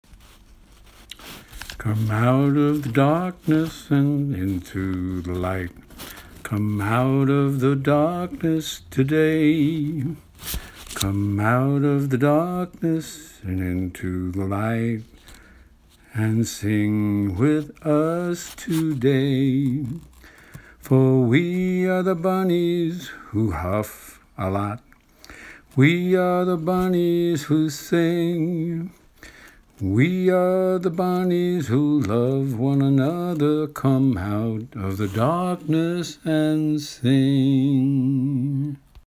initial demo (C#),